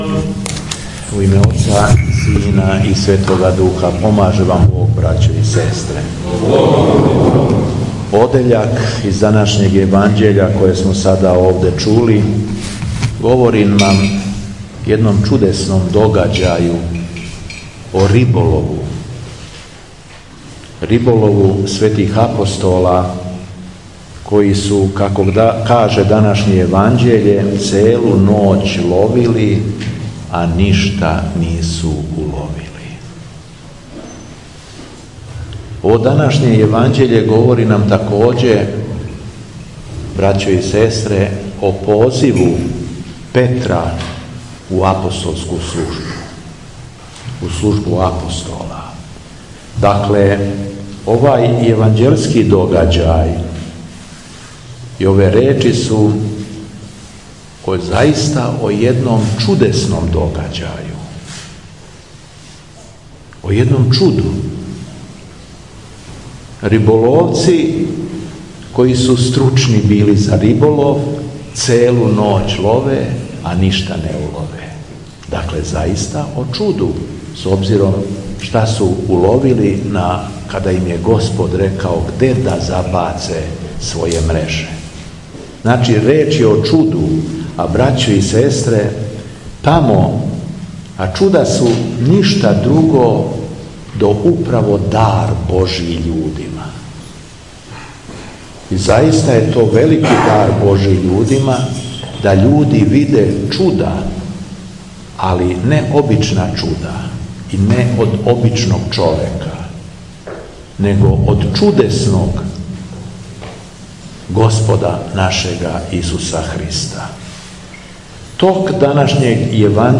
СВЕТА АРХИЈЕРЕЈСКА ЛИТУРГИЈА У ХРАМУ СВЕТОГ ВЕЛИКОМУЧЕНИКА ГЕОРГИЈА У ЧИБУТКОВИЦИ - Епархија Шумадијска
Беседа Његовог Преосвештенства Епископа шумадијског Г. Јована